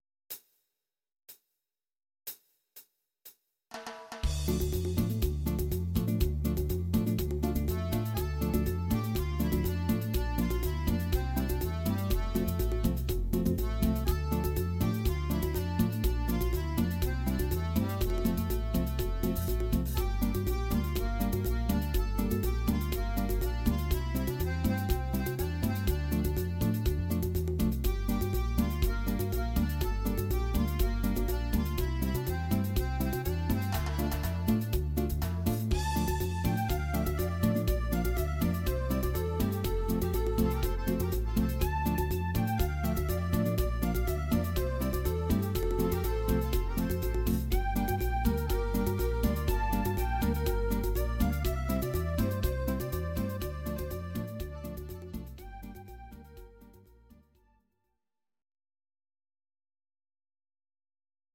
Audio Recordings based on Midi-files
Pop, 1980s